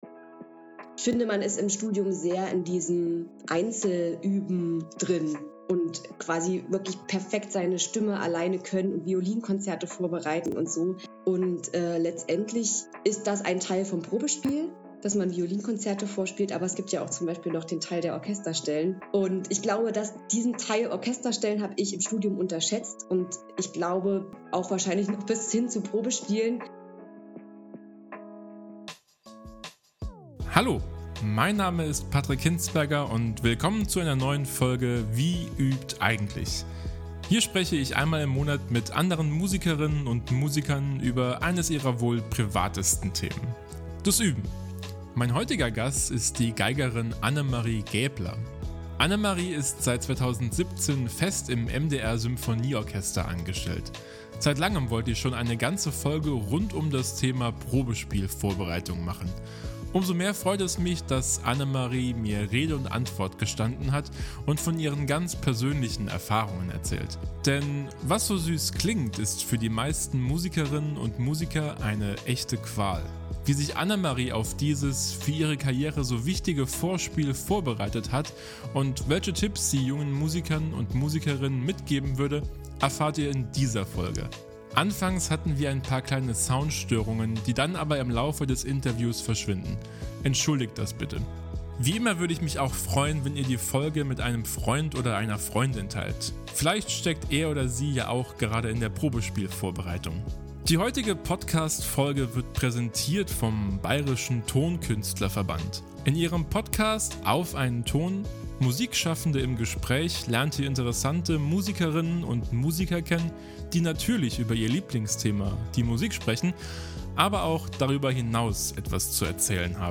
Anfangs hatten wir ein paar kleine Sound-Störungen, die dann aber im Laufe des Interviews verschwinden.